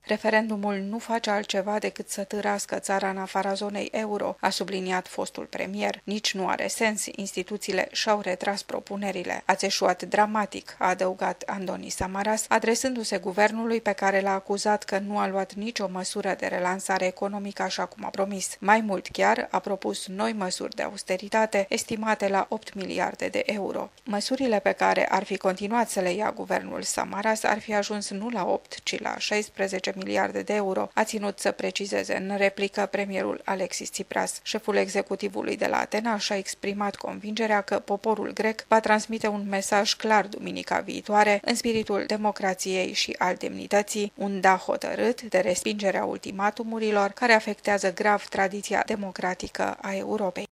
transmite din Atena: